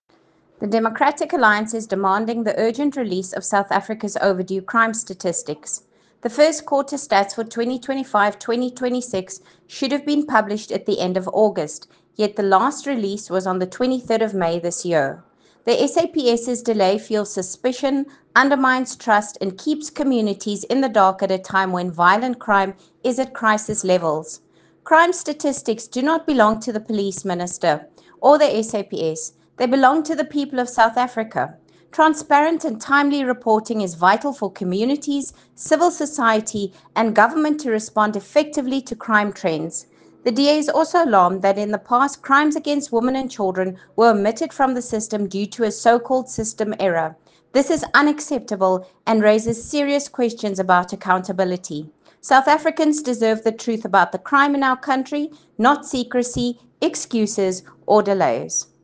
Issued by Lisa Schickerling MP – DA Spokesperson on Police